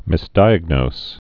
(mĭs-dīəg-nōs, -nōz)